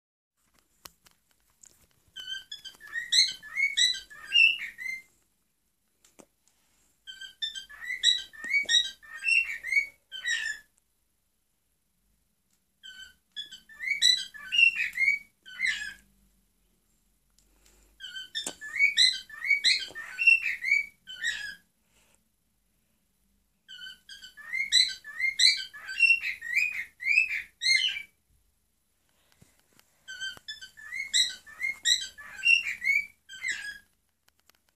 Unsere Nymphensittiche
Zu unserer Überraschung kann Fritzi schön singen (Fritzi1.mp3,